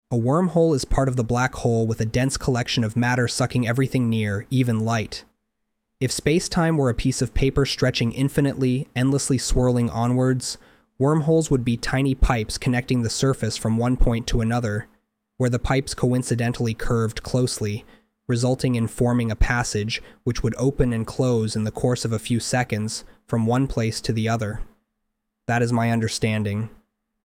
IFYLITA Paragraph Sample.mp3
Audiobooks for BL fans